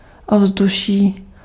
Ääntäminen
Vaihtoehtoiset kirjoitusmuodot (vanhentunut) atmosphære Synonyymit feeling mood ambiance air lift Ääntäminen US : IPA : [ˈætməsˌfɪɹ] UK : IPA : /ˈæt.məsˌfɪə(ɹ)/ Tuntematon aksentti: IPA : /ˈæt.məsˌfɛə(ɹ)/